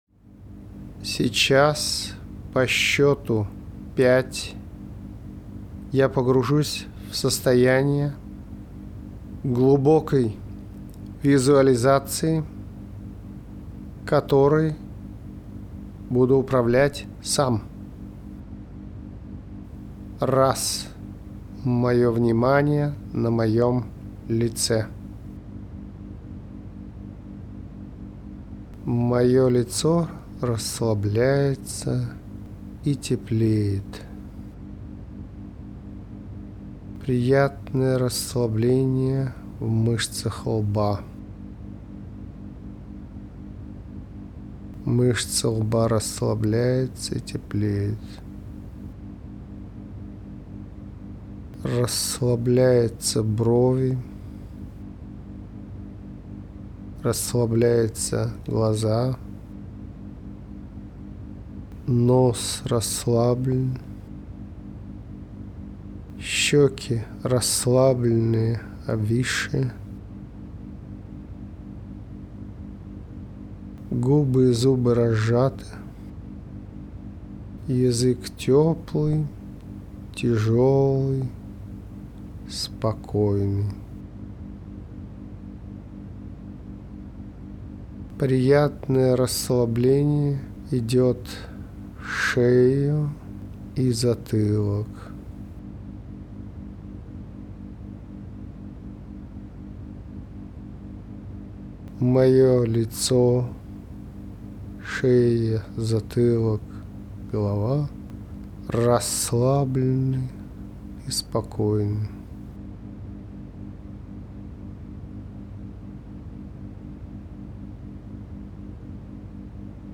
Аудиопрактика